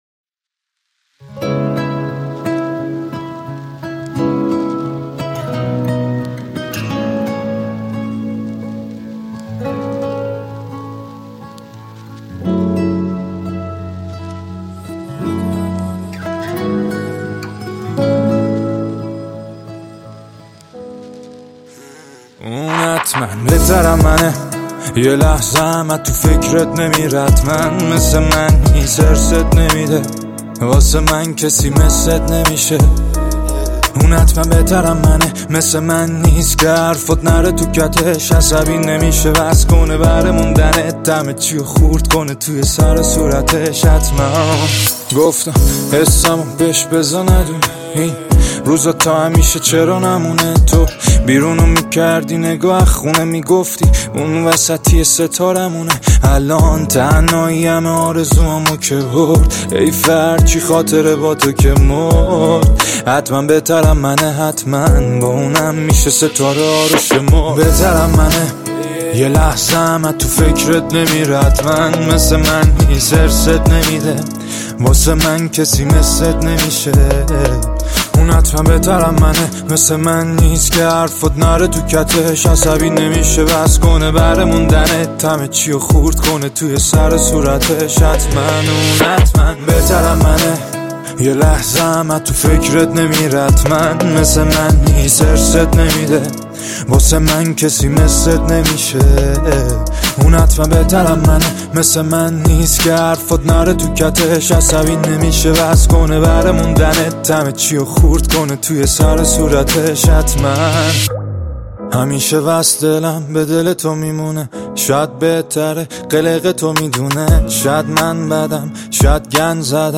دانلود آهنگ غمگین و قشنگ